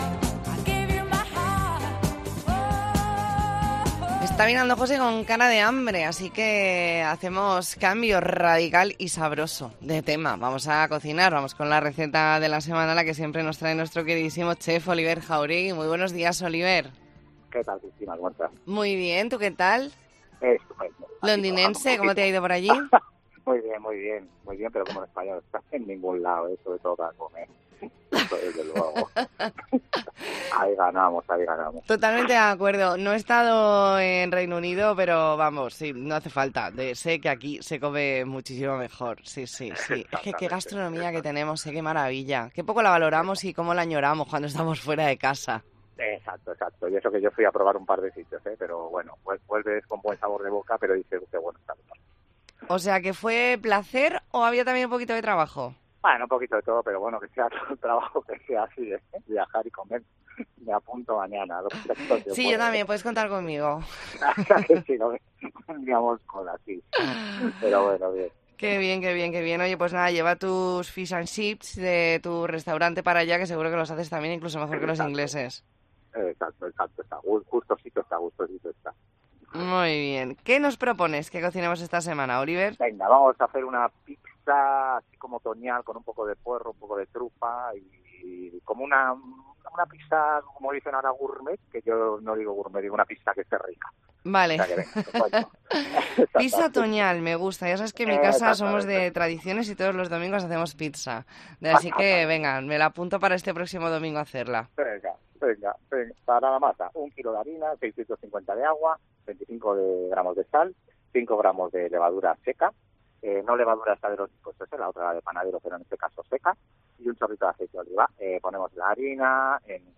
Entrevista en La Mañana en COPE Más Mallorca, lunes 30 de septiembre de 2023.